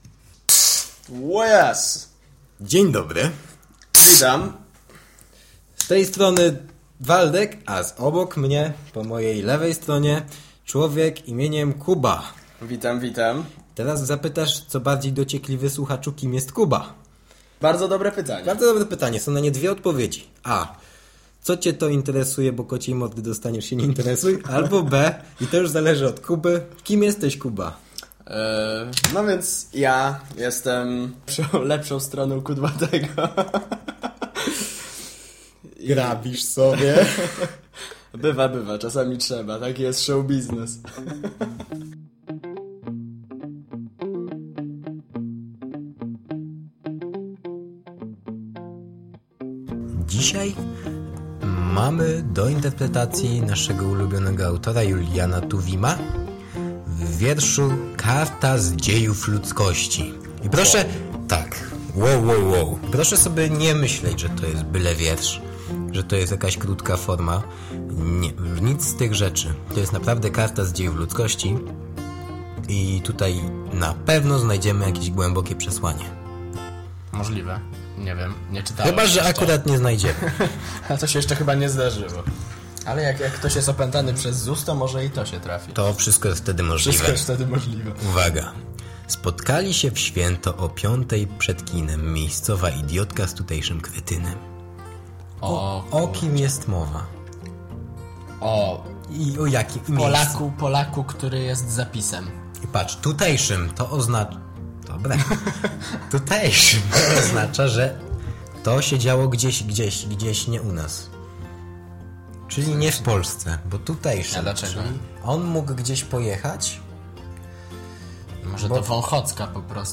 A w nim, oczywiście bujna interpretacja, dużo śmiechu i troszkę prawd życiowych.
"Co Ałtor Miał na Myśli" to audycja rozrywkowa, nagrywana co tydzień lub dwa.